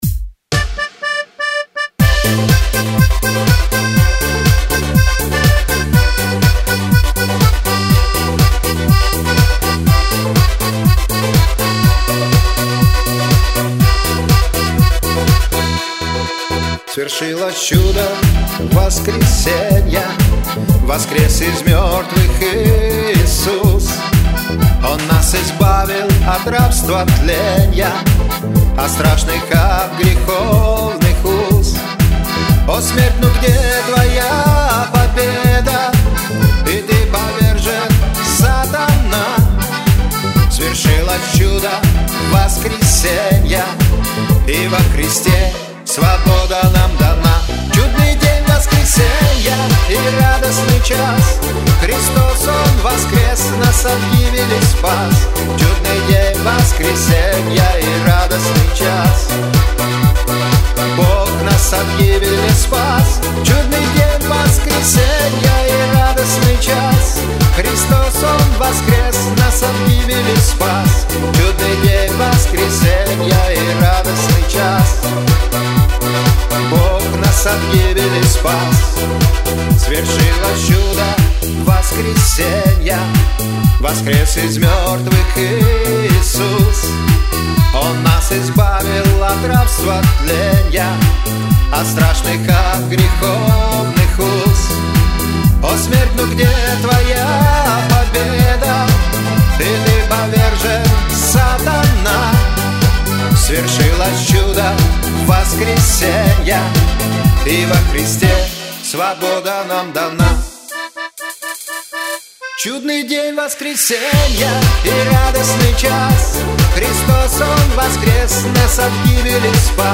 песня
150 просмотров 352 прослушивания 6 скачиваний BPM: 122